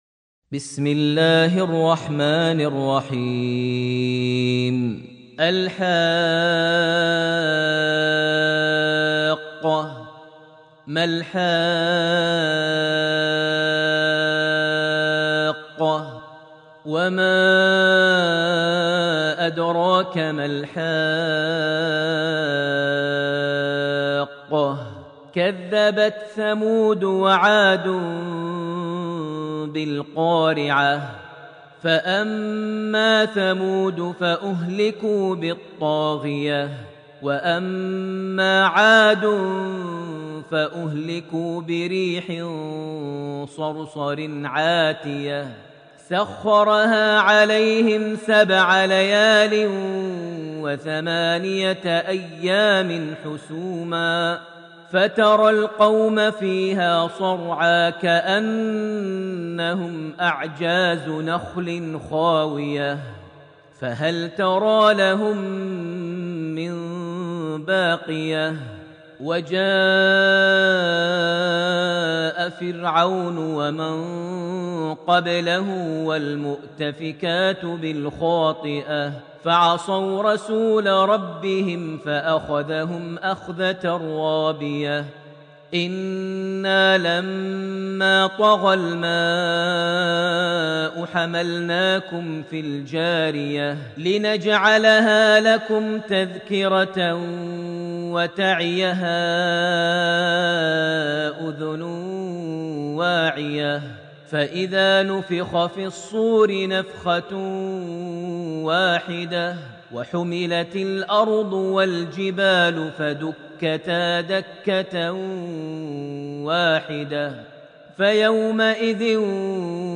Surah Al-Haqa > Almushaf > Mushaf - Maher Almuaiqly Recitations